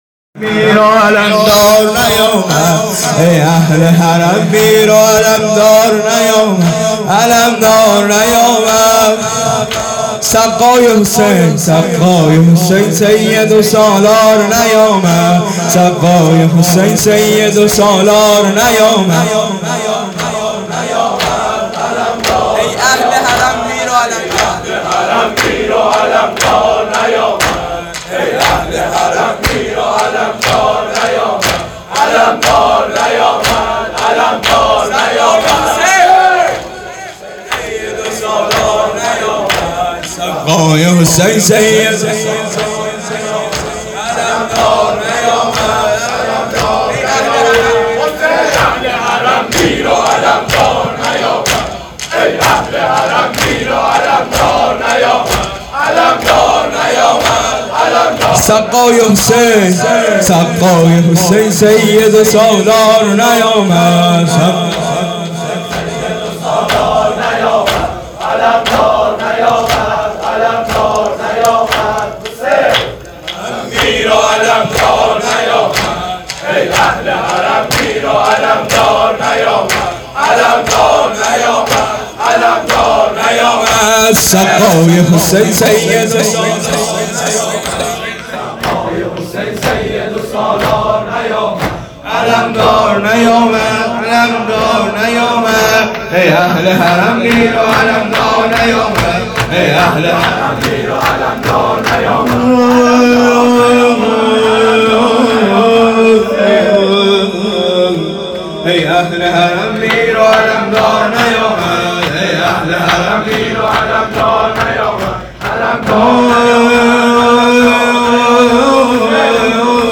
خیمه گاه - کمیت 135 - دودمه ی ای اهل حرم میر و علمدار نیامد
هیات قاسم ابن الحسن